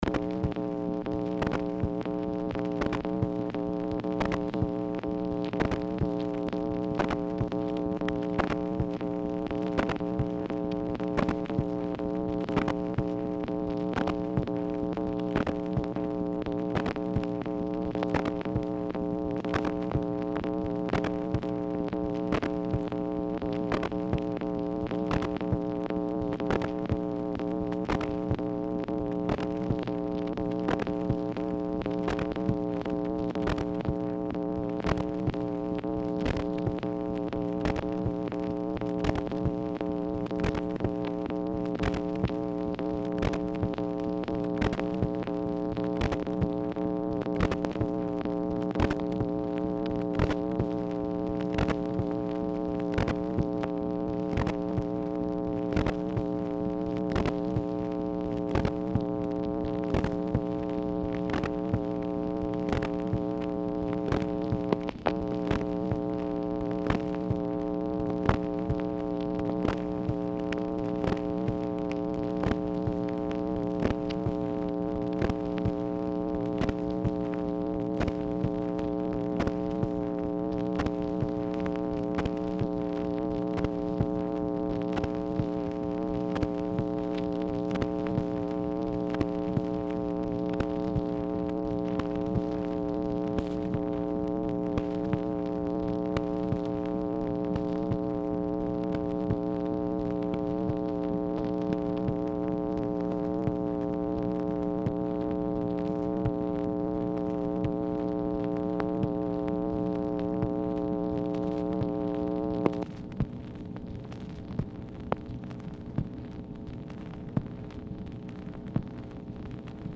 Telephone conversation # 5123, sound recording, MACHINE NOISE, 8/22/1964, time unknown | Discover LBJ
Format Dictation belt